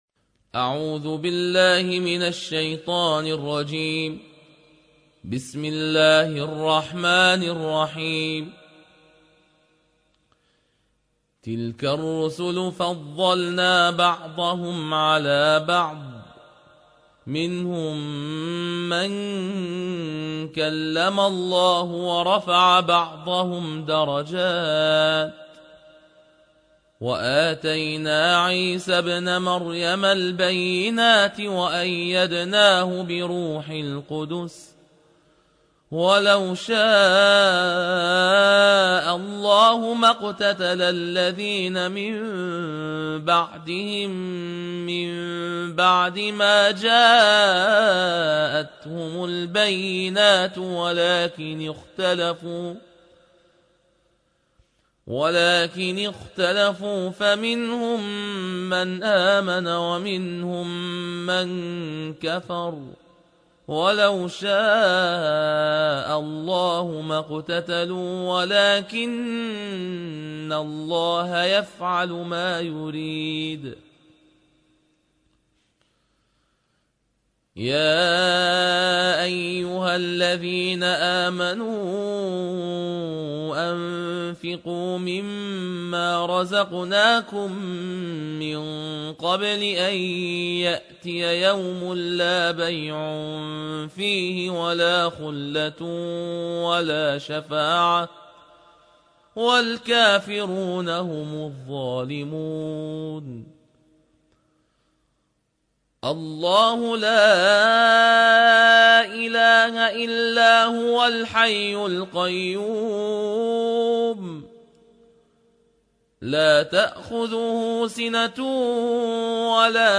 الجزء الثالث / القارئ